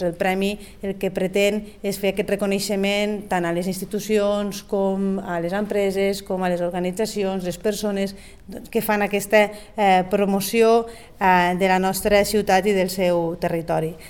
Tall de veu Montse Mínguez